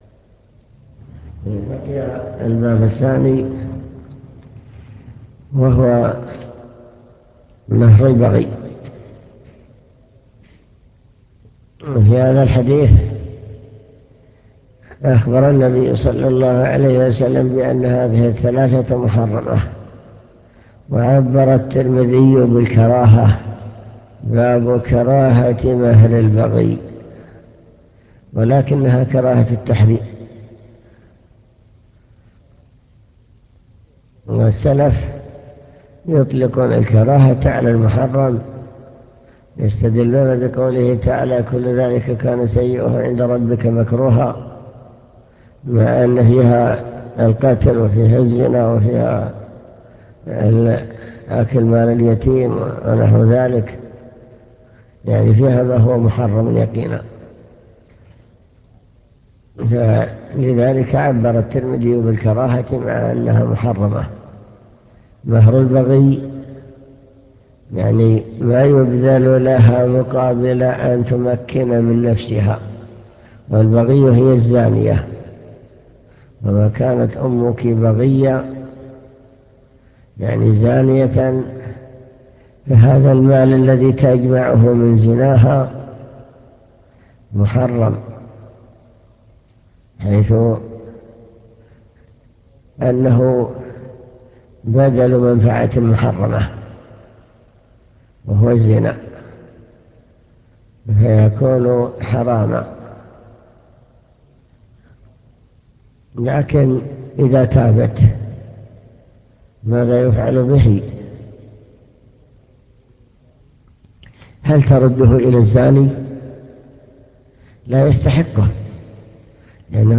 المكتبة الصوتية  تسجيلات - كتب  شرح سنن الترمذي كتاب النكاح